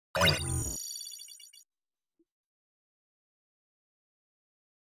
دانلود صدای ربات 24 از ساعد نیوز با لینک مستقیم و کیفیت بالا
جلوه های صوتی